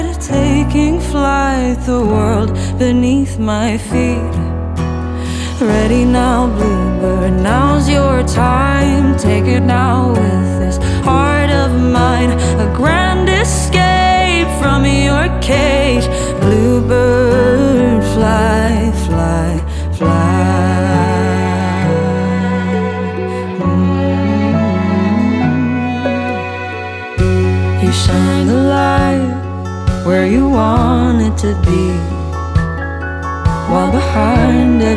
Alternative Pop
Жанр: Поп музыка / Альтернатива